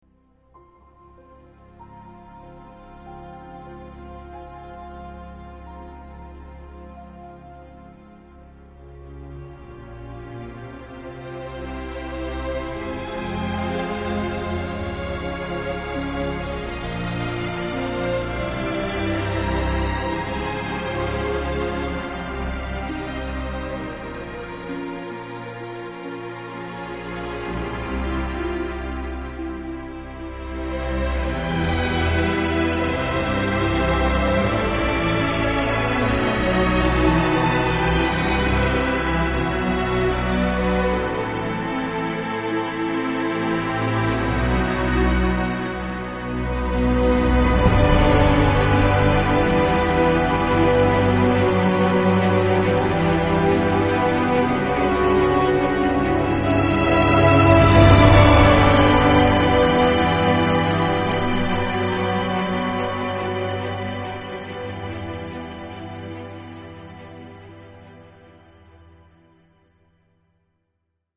ブルースが不安と苦悩の中で生きていく様を描いたスコアは絶品で、非常に感極まるものがあります。